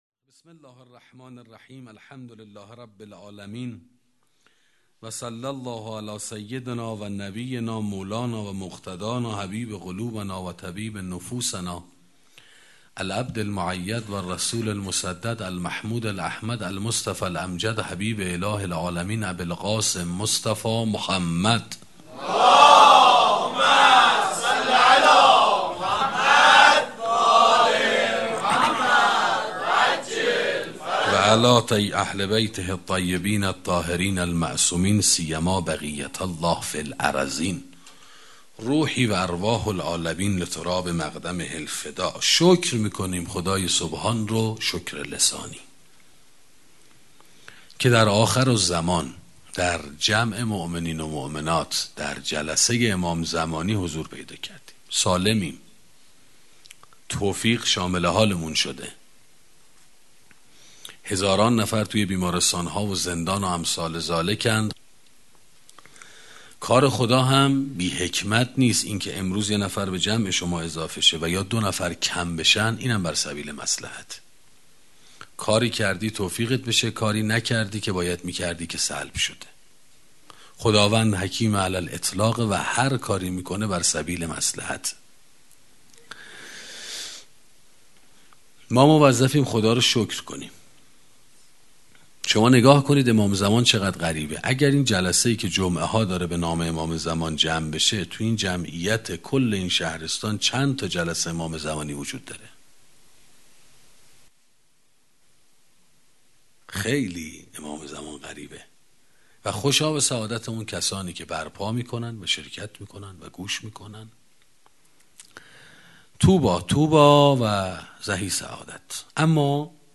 سخنرانی وحدت